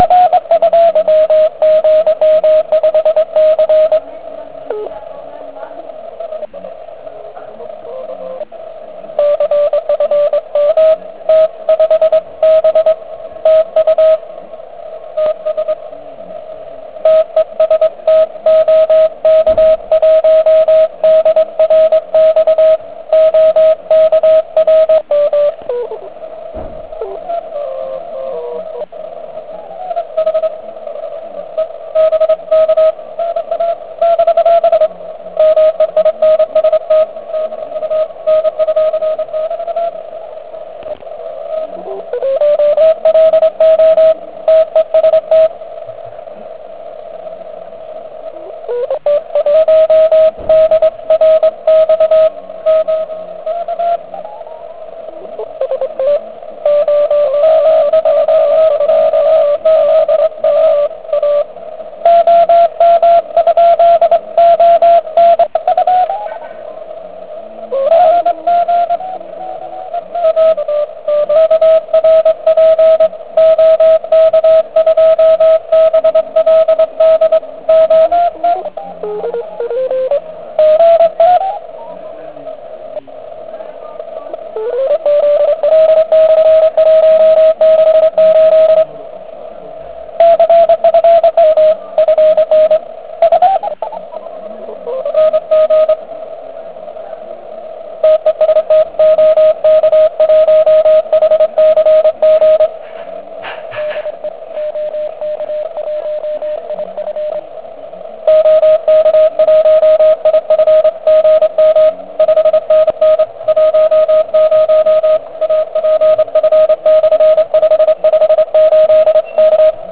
Záznam "kusu" závodu (*.wav 2,8MB)